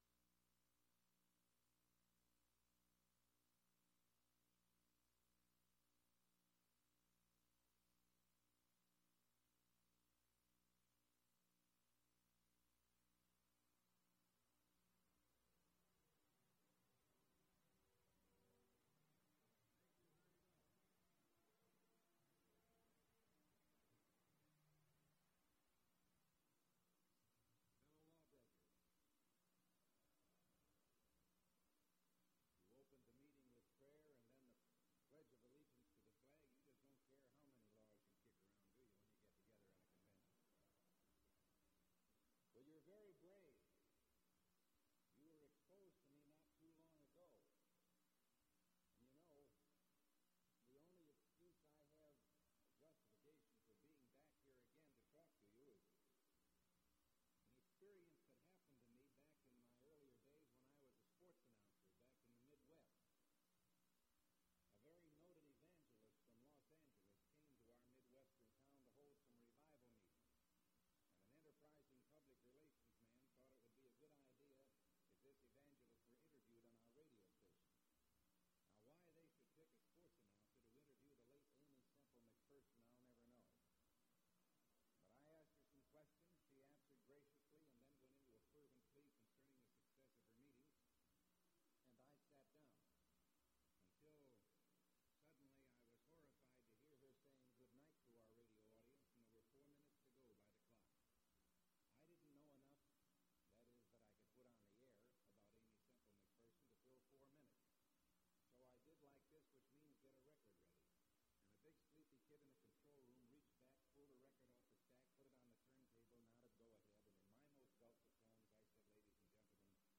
Ronald Reagan speaking to the Realtors Convention, First Methodist Church, Los Angeles, California
Audio Cassette Format (3 copies).